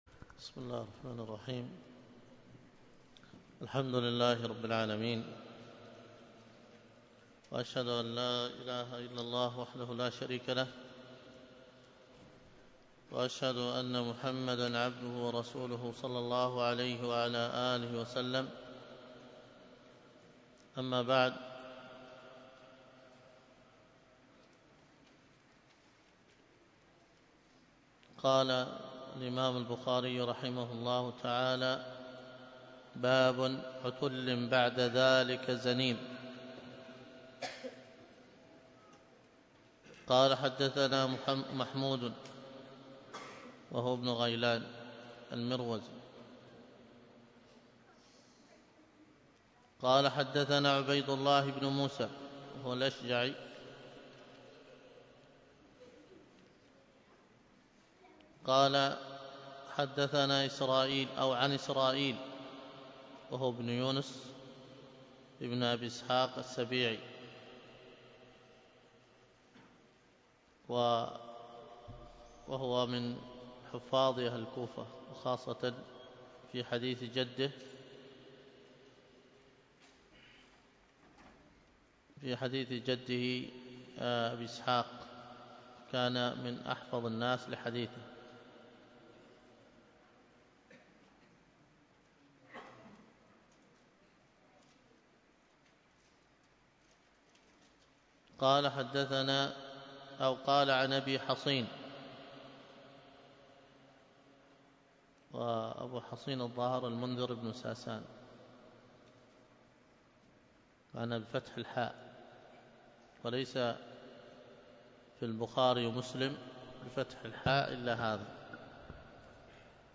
الدروس الفقه وأصوله